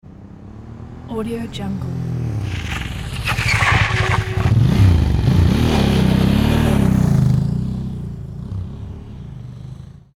دانلود افکت صوتی ترمز گرفتن شدید موتور سیکلت سنگین و حرکت مجدد